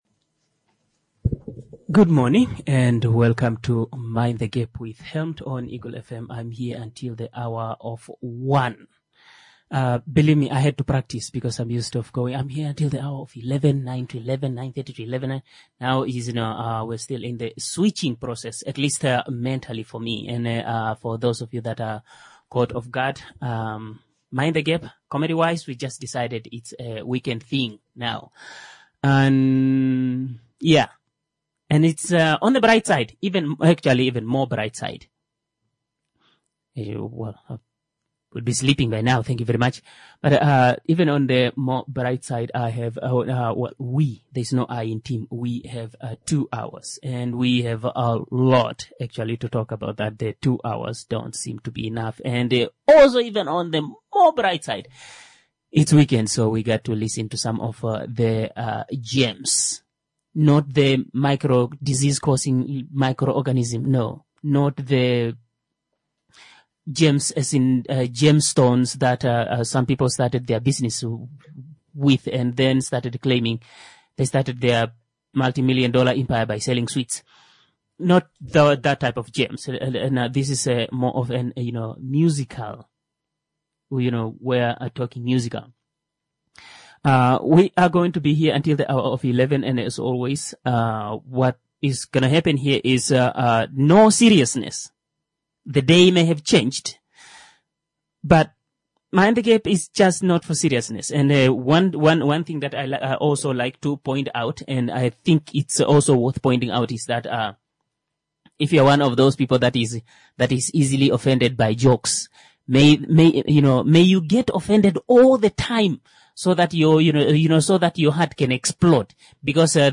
⚠ This is a COMEDY show.